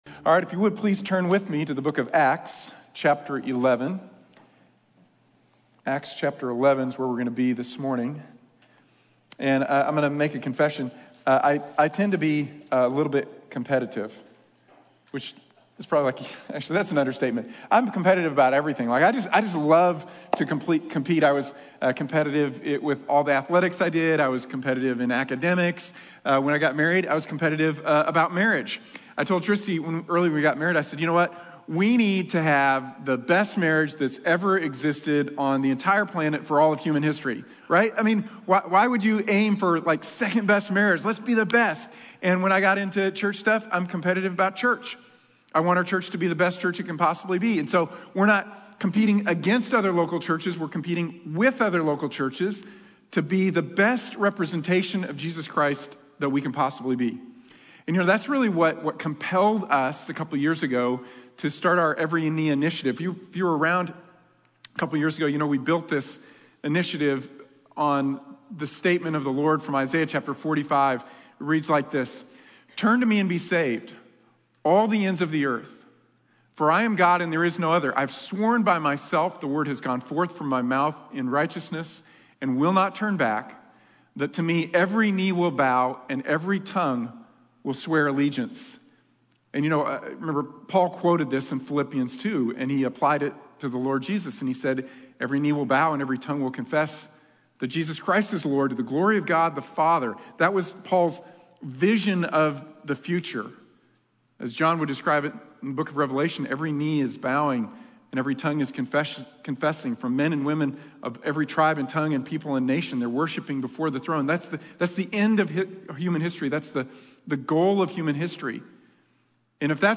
Every Knee | Sermon | Grace Bible Church
Anderson Campus